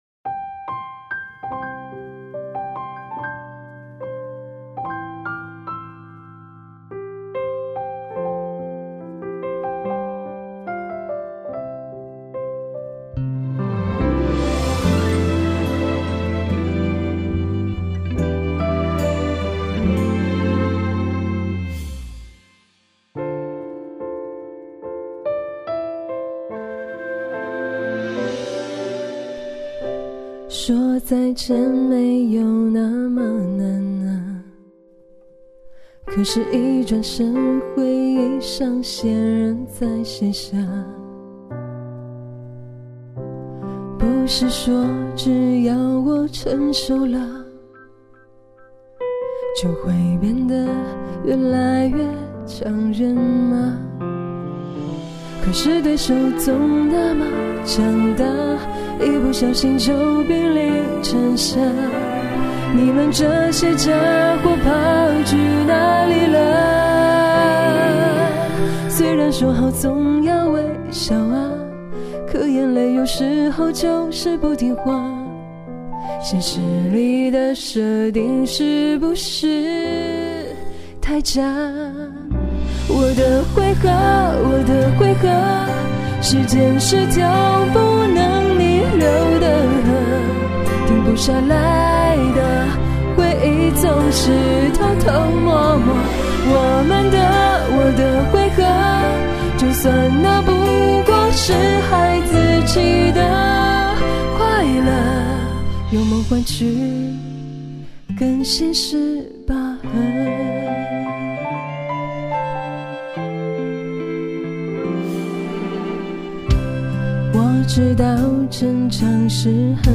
♦音乐盛宴♦玩家优秀翻唱作品展示